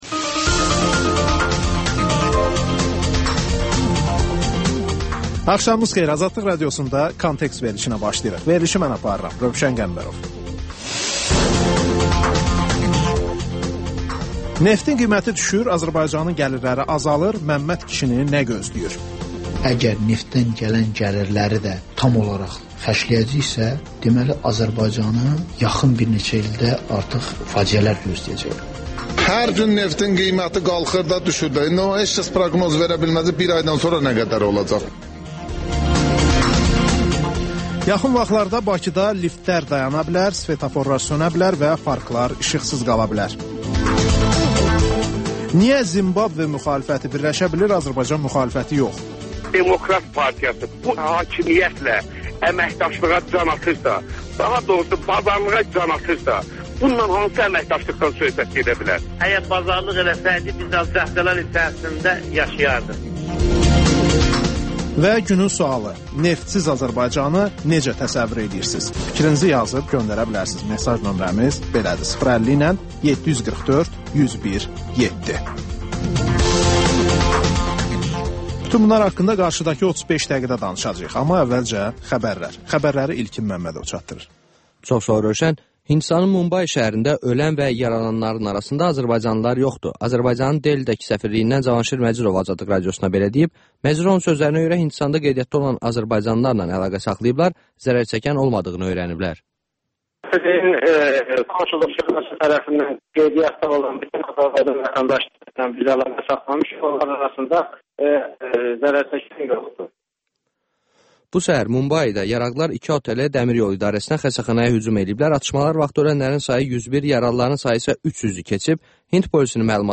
Xəbərlər, müsahibələr, hadisələrin müzakirəsi, təhlillər, sonda TANINMIŞLAR: Ölkənin tanınmış simaları ilə söhbət